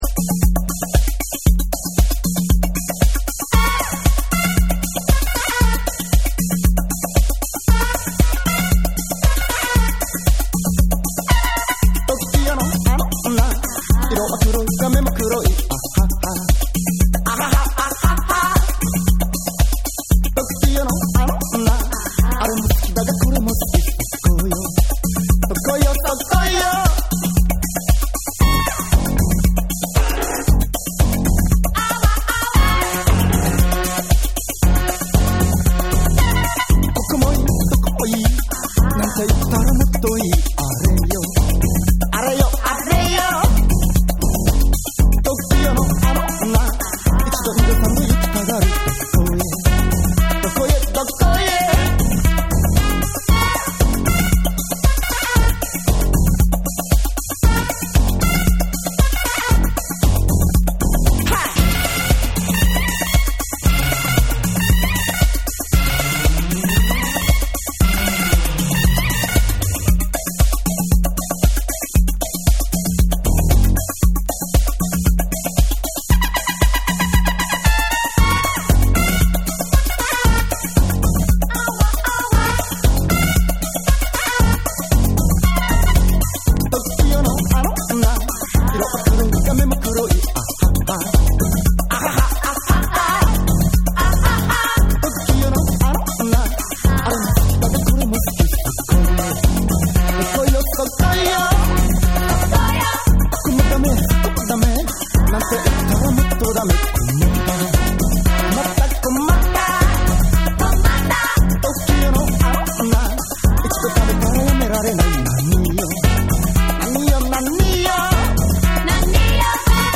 JAPANESE / NEW WAVE & ROCK